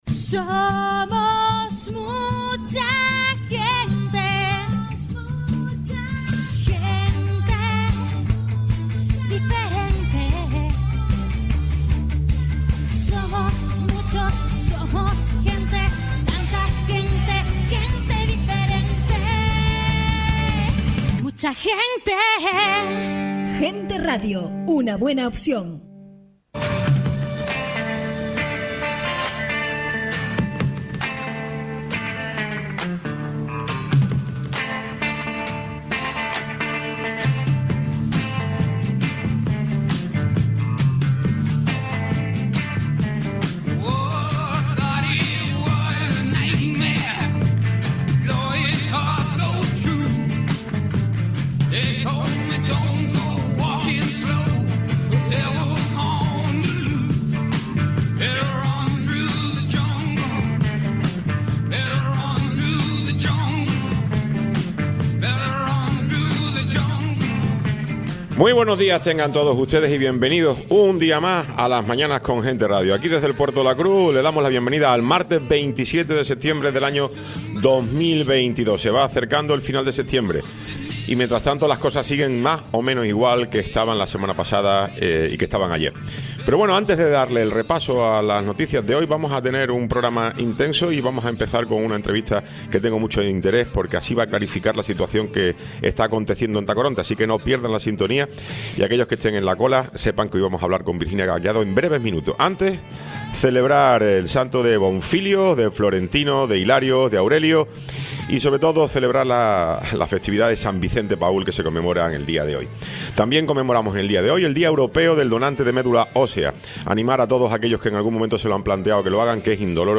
Tiempo de entrevista con Virginia Bacallado, concejala Ciudadanos Tacoronte